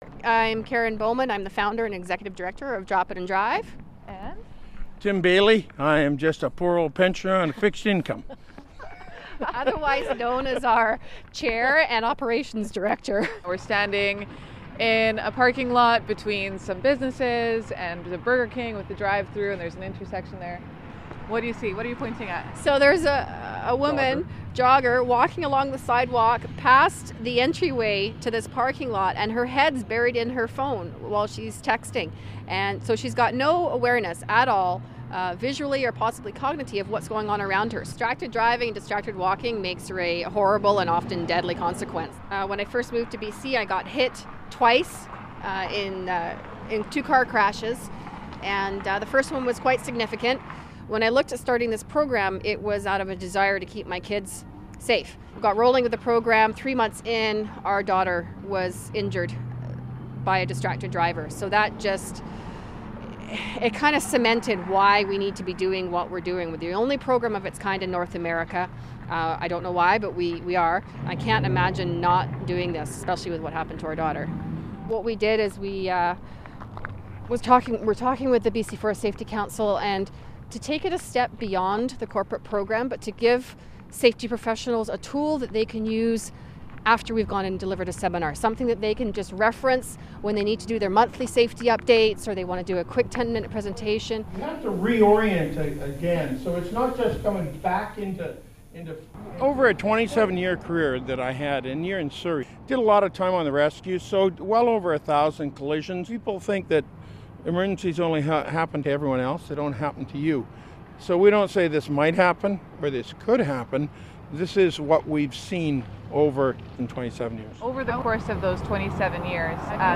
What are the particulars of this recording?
took them to a Prince George sidewalk to find some flaws with local driving habits.